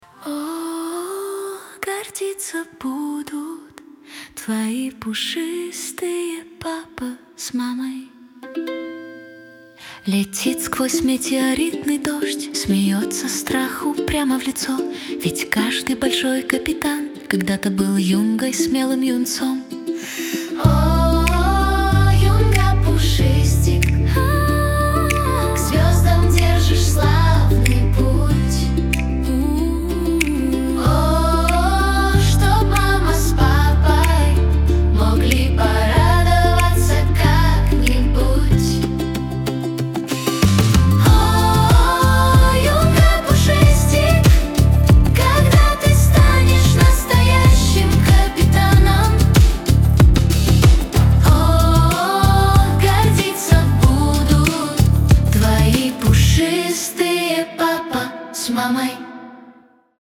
Финальная песня). Саундтрек